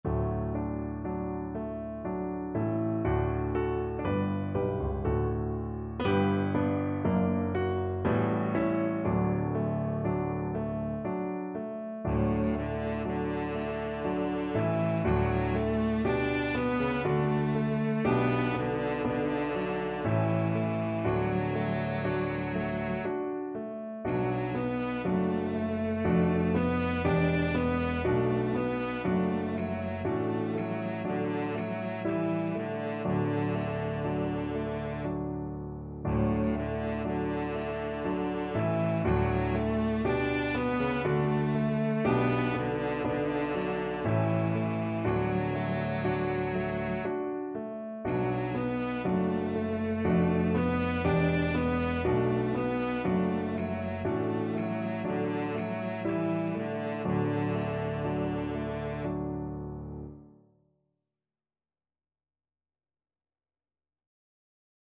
Traditional Trad. Aka Tombo Cello version
World Asia Japan Aka Tombo
Cello
3/4 (View more 3/4 Music)
D major (Sounding Pitch) (View more D major Music for Cello )
Lento e tranquillo (=60)